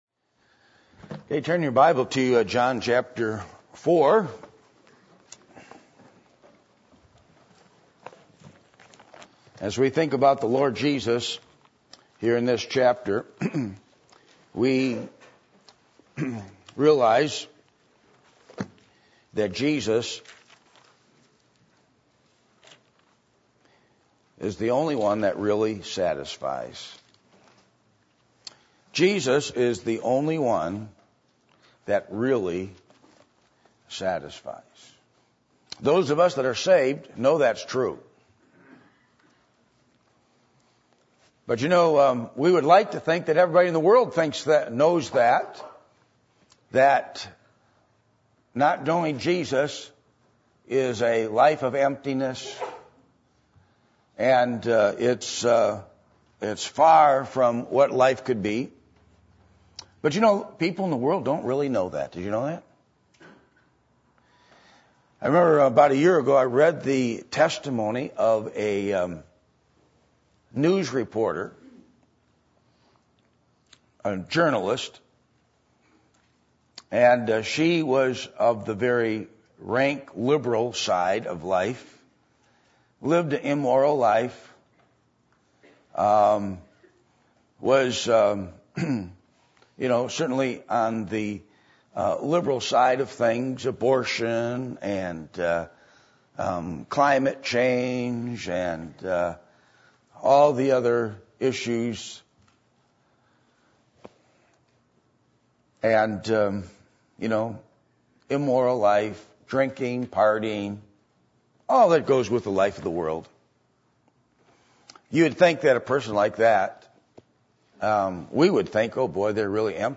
Passage: John 4:1-39 Service Type: Sunday Morning %todo_render% « A Picture Of Worship